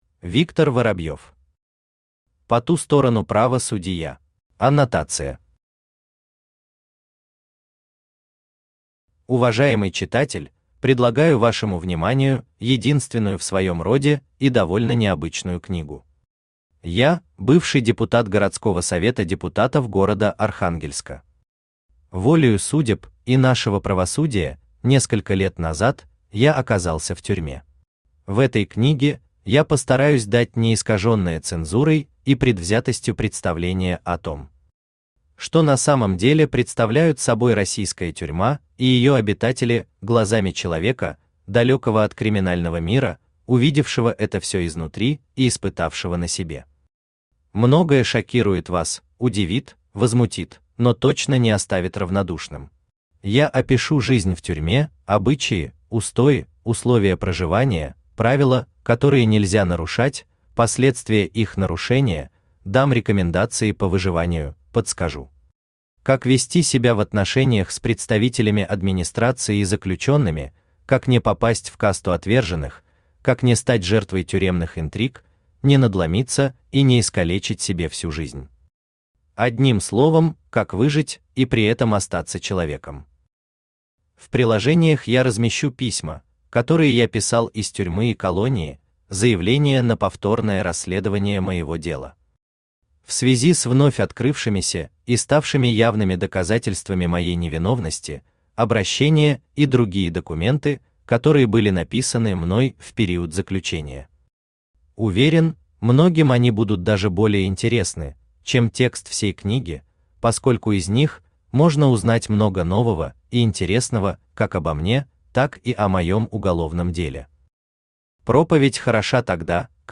Аудиокнига По ту сторону ПравоСудиЯ | Библиотека аудиокниг
Aудиокнига По ту сторону ПравоСудиЯ Автор Виктор Николаевич Воробьев Читает аудиокнигу Авточтец ЛитРес.